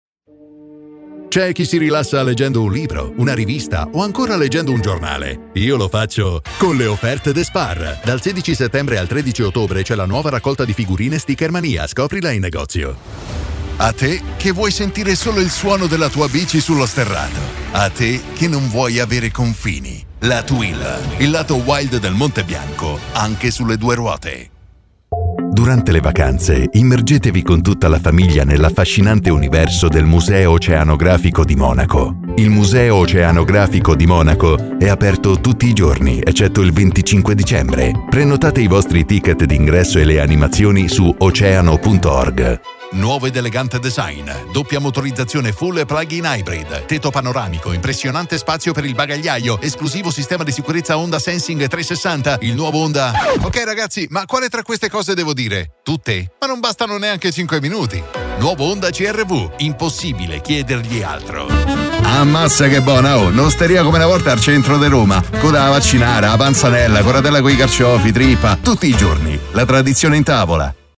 Promos
My voice is deep, mature, warm and enveloping, but also aggressive, emotional and relaxing.